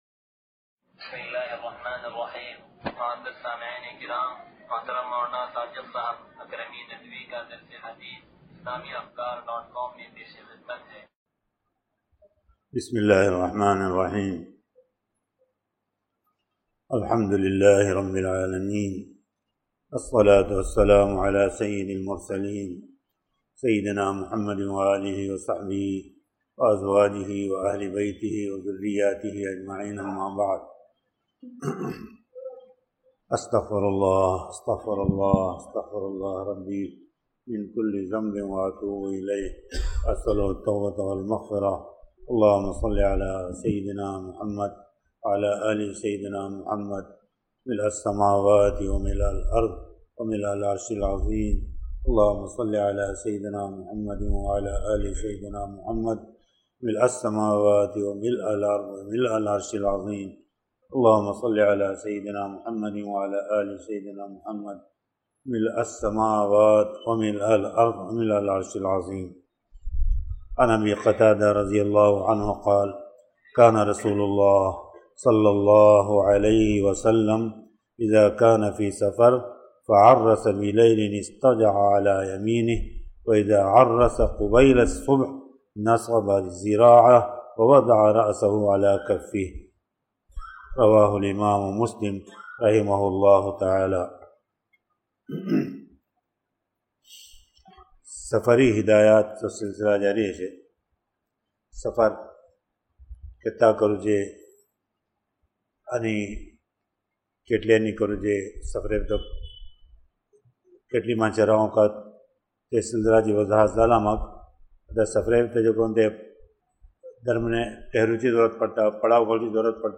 درس حدیث نمبر 0754
سلطانی مسجد